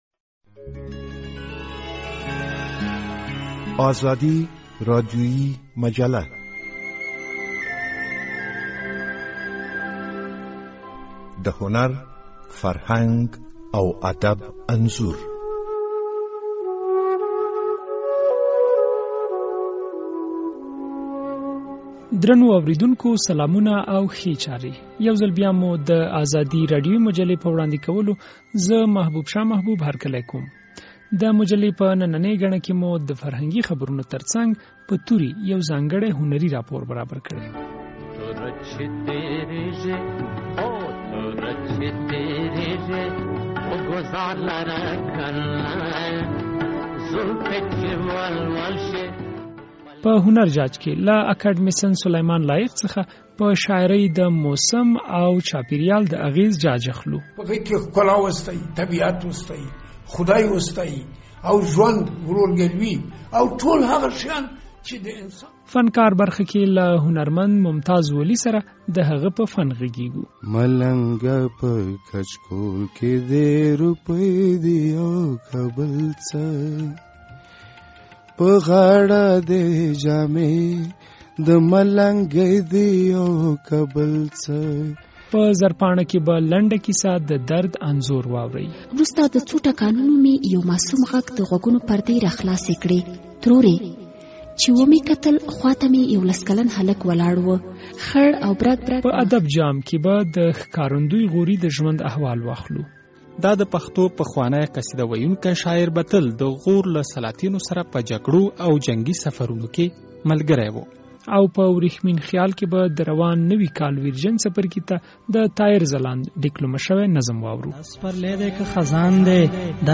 راډیويي مجله